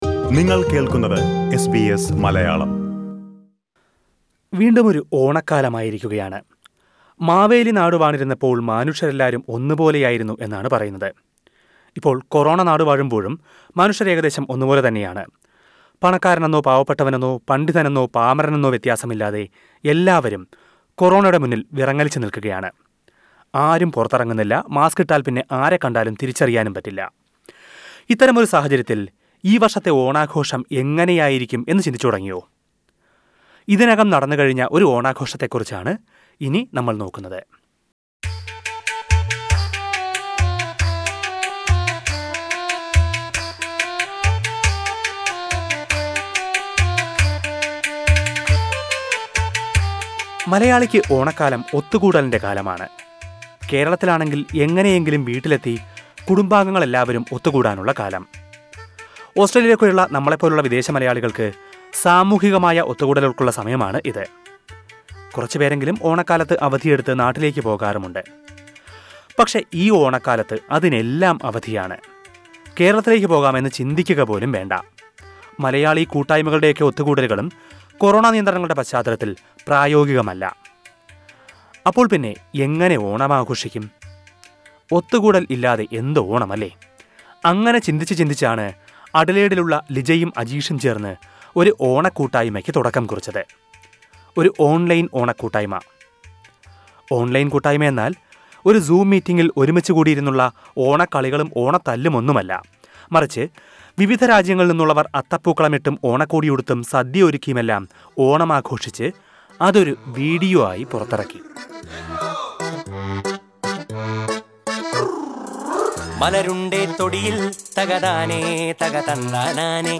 Onam celebrations are changing in the Covid times. l43 old classmates from the M V Shetty College of Nursing in Mangalore have come together from various countries to celebrate Onam online. Listen to a report..